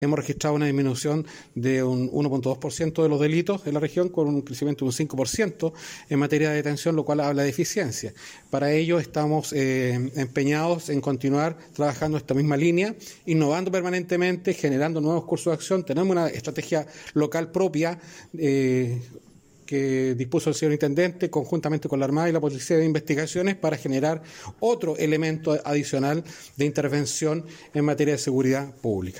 En dicho contexto, el General de la X Zona de Carabineros, Patricio Yáñez comentó que han registrado una disminución de 1,2% de los delitos, con un crecimiento del 5% en el rango de detención.